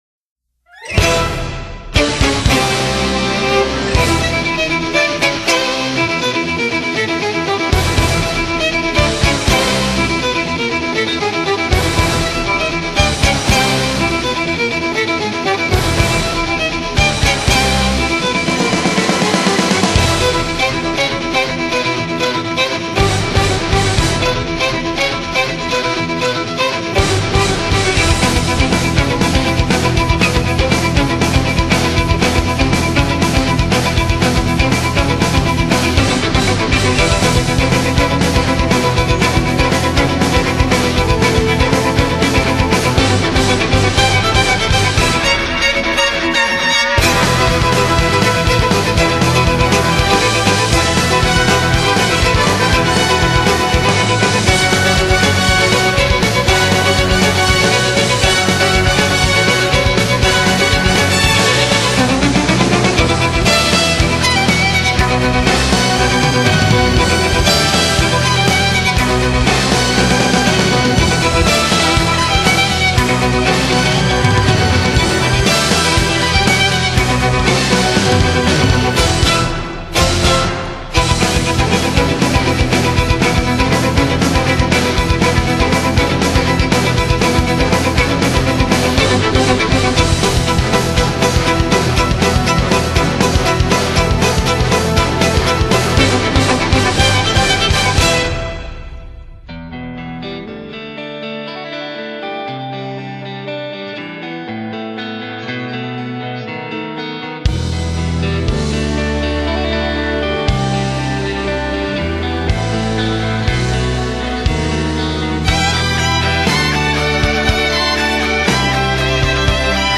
音樂風格︰Classical | 1CD |